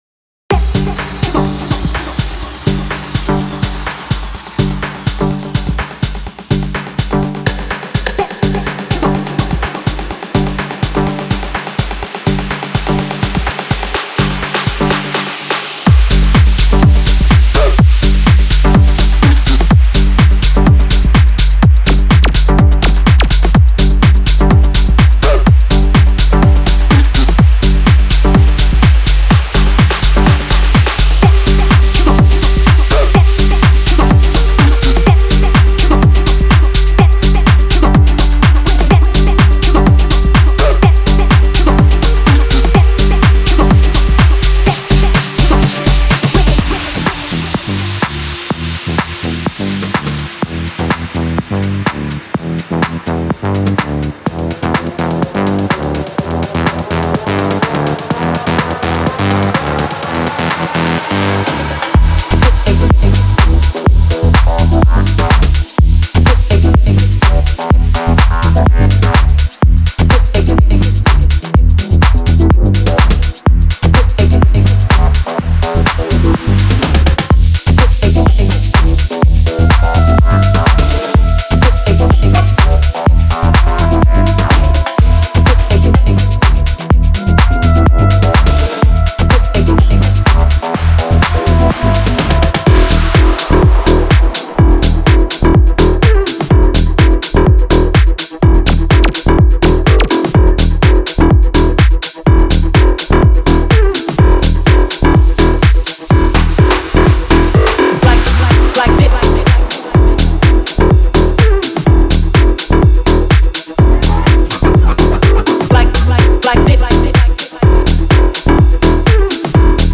Genre:Tech House
これはアンダーグラウンドハウスの微細でグルーヴィー、かつ催眠的な側面を探求する新しいコレクションです。
その代わりに、深く温かみのある、身体を揺らすグルーヴを提供し、静かに夜遅くのダンスフロアの雰囲気へと誘います。
エネルギーは安定し、催眠的に持続します。
デモサウンドはコチラ↓
125 BPM